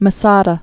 (mə-sädə, -tsä-dä)